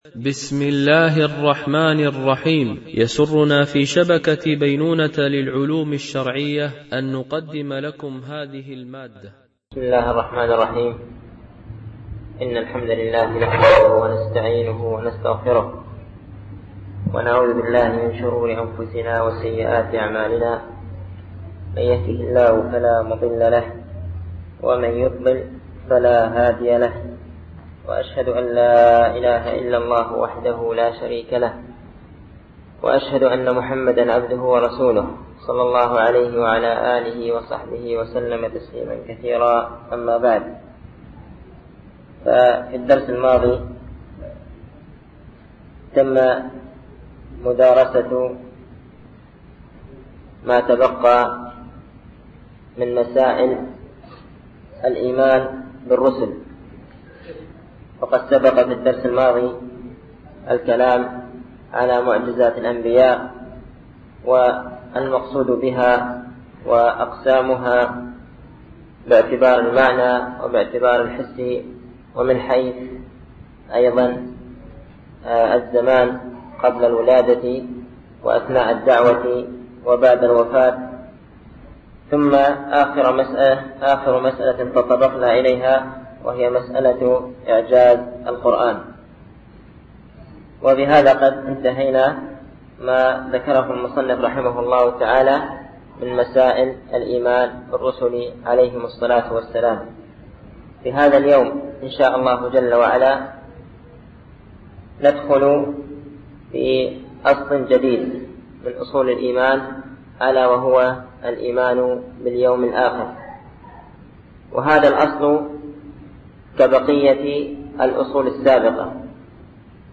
الألبوم: شبكة بينونة للعلوم الشرعية التتبع: 37 المدة: 41:00 دقائق (9.42 م.بايت) التنسيق: MP3 Mono 22kHz 32Kbps (CBR)